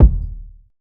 Ballin Out Kick.wav